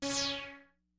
Korg Z1 Z1 FM Noise Bass " Korg Z1 Z1 FM Noise Bass C5 ( Z1 FM Noise Bass73127)
标签： CSharp5 MIDI音符-73 Korg的-Z1 合成器 单票据 多重采样
声道立体声